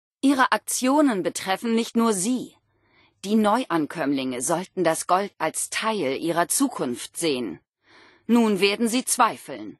Wastelanders: Audiodialoge
Beschreibung Lizenz Diese Datei wurde in dem Video-Spiel Fallout 76 aufgenommen oder stammt von Webseiten, die erstellt und im Besitz von Bethesda Softworks sind, deren Urheberrecht von Bethesda Softworks beansprucht wird.